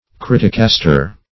Search Result for " criticaster" : The Collaborative International Dictionary of English v.0.48: Criticaster \Crit"ic*as`ter\ (kr[i^]t"[i^]k*[a^]s`t[~e]r), n. A contemptible or vicious critic.